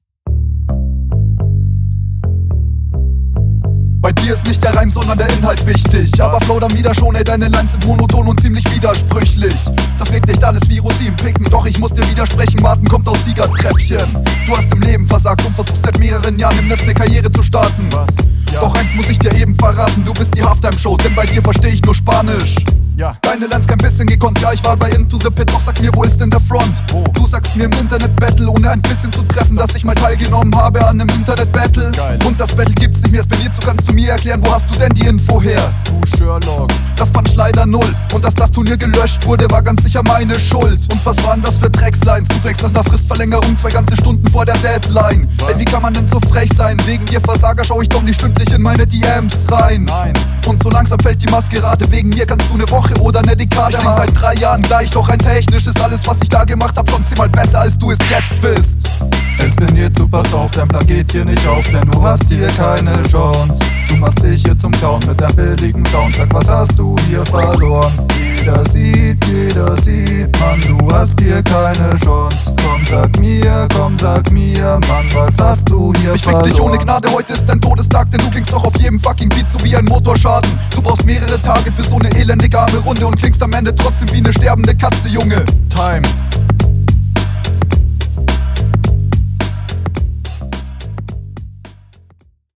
Kleiner Schmatzer oder Mausklick am Anfang, aber juckt.
Bei der Hook liegt die tiefere Spur irgendwie nur auf dem Rechten Kanal (oder zumindest …